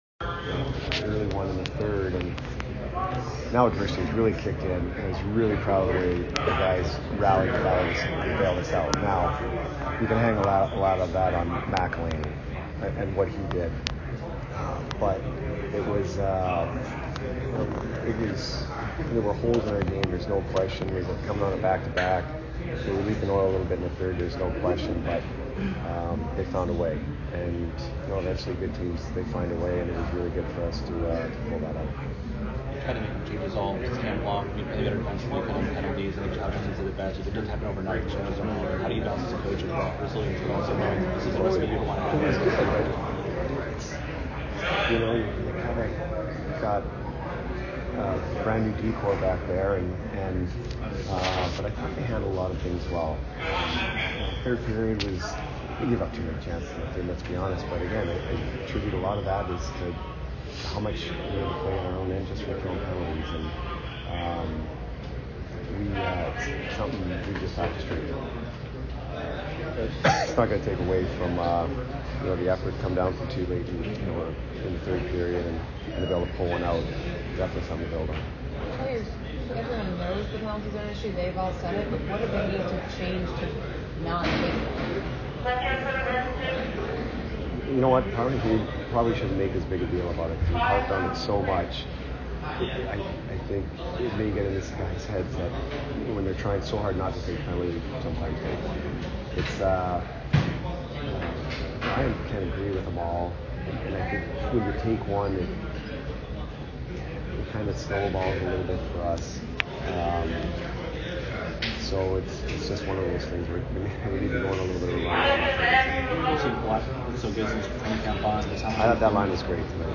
Jon Cooper Post - Game At New Jersey Oct. 30, 2019